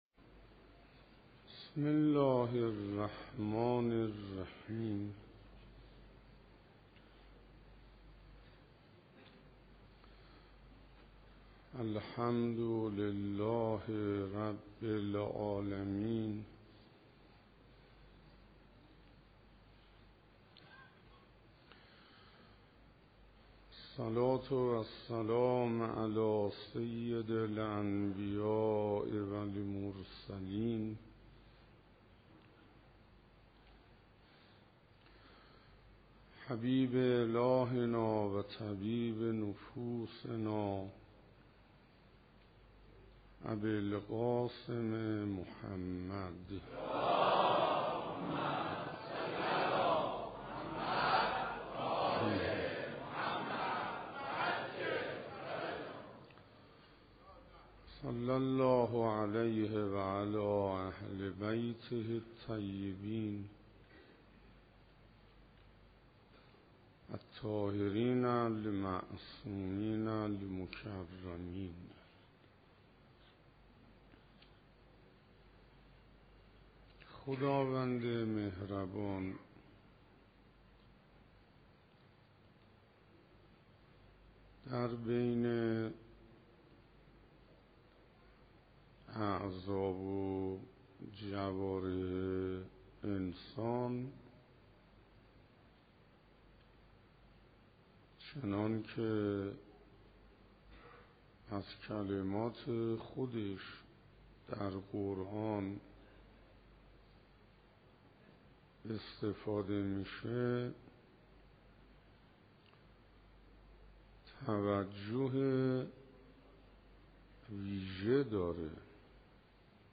شب دوازدهم حسینیه همدانی ها رمضان 1396_سلوک معنوی
سخنرانی